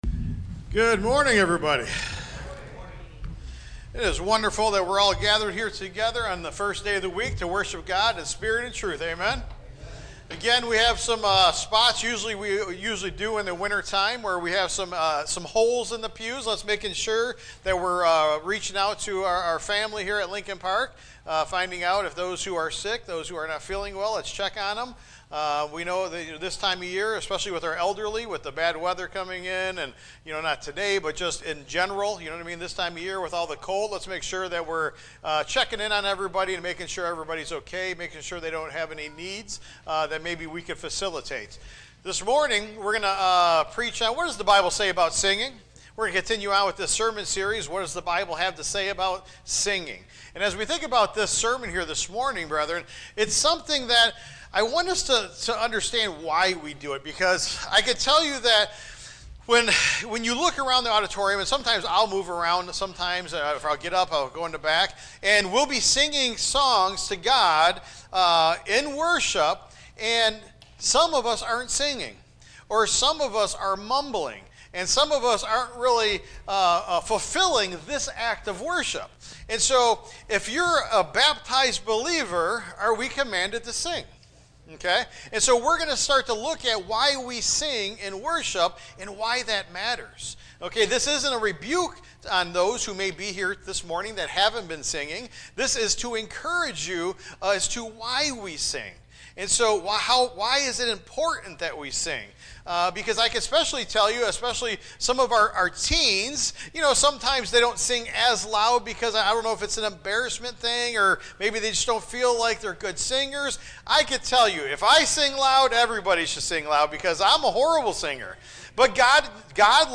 Scripture Referenced Ephesians 5:17–19 Colossians 3:16 Ephesians 5:20–21 Hebrews 13:15 Tagged with sermon Audio (MP3) 11 MB Previous The Pond and the Tree Next When Church Hurts